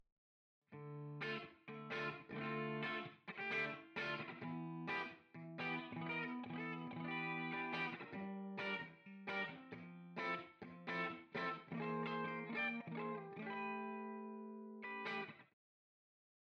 Αναλογική αισθητική στην ψηφιακή εποχή: Συγκριτική μελέτη αναλογικών και ψηφιακών τεχνικών στην ηχογράφηση και μίξη της ηλεκτρικής κιθάρας